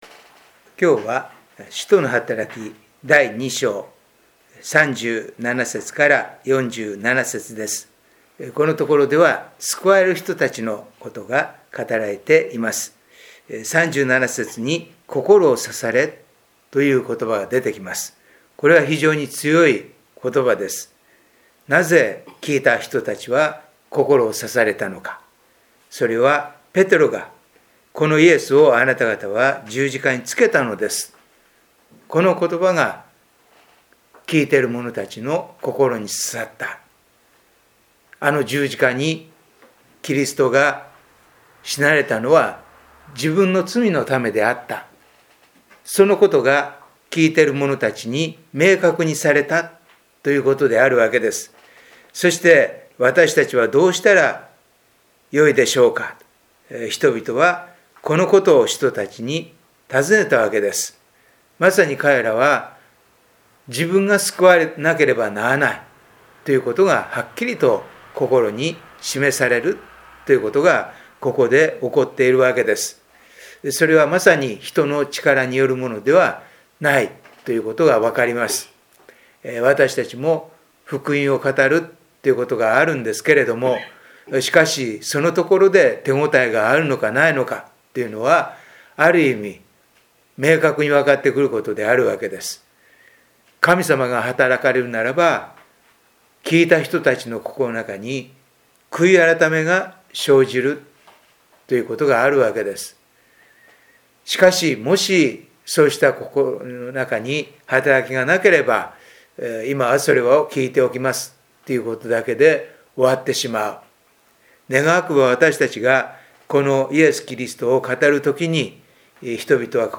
聖書研究祈祷会動画│日本イエス・キリスト教団 柏 原 教 会